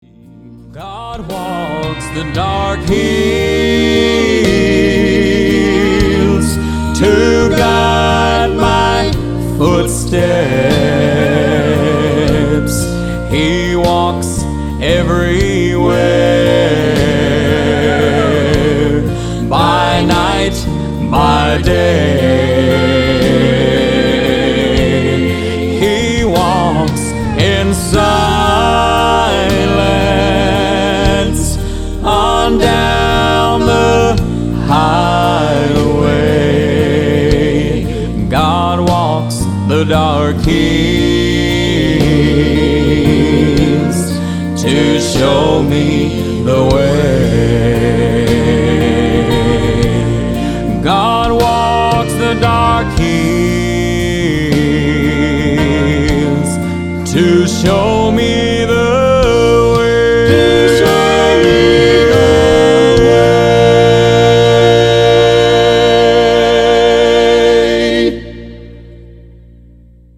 11 Southern Gospel Songs